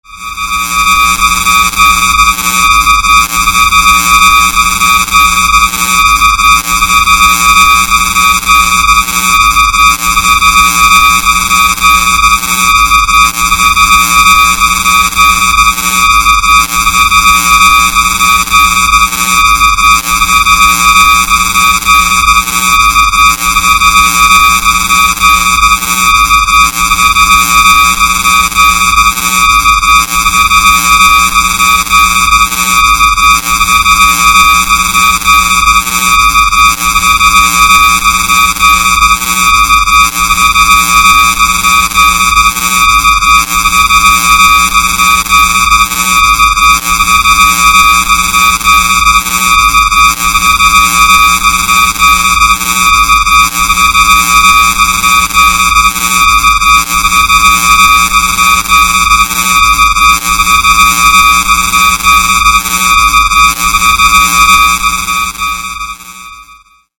Высокочастотный звук для беспокойства соседей (громко слушать не стоит)